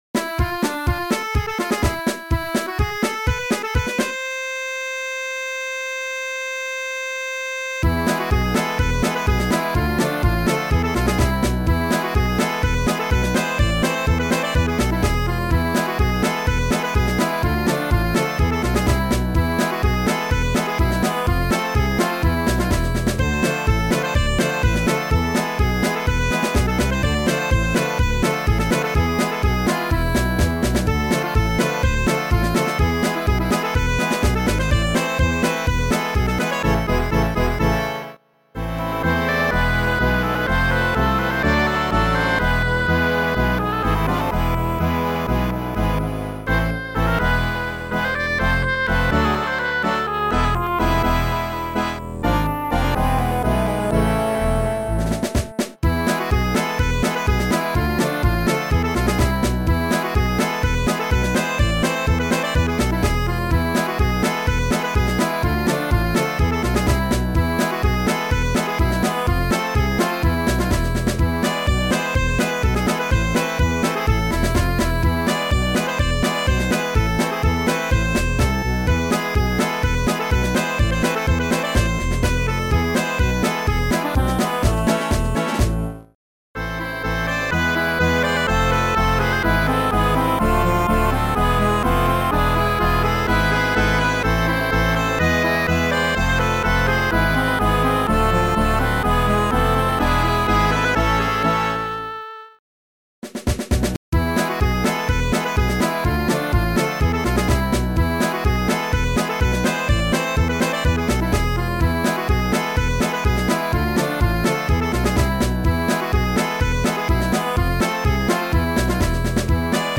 Sound Format: Noisetracker/Protracker